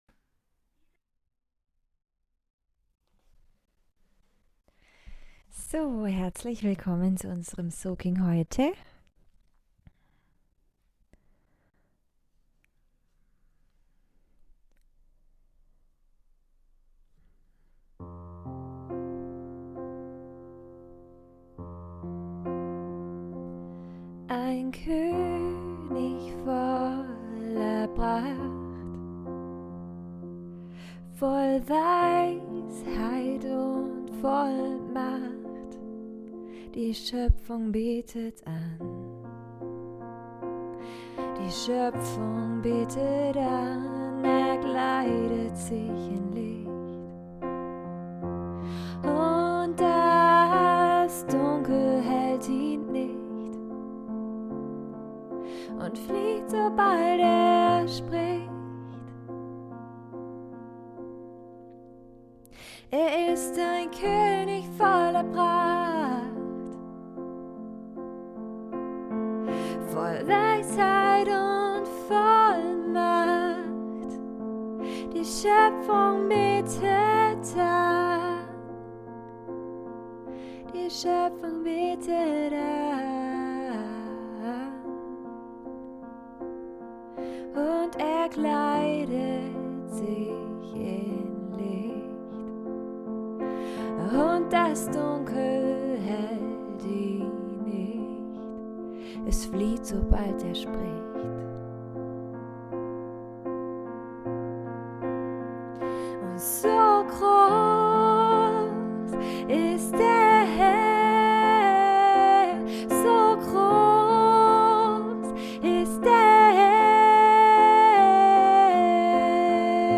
2021-06-22 – Soaking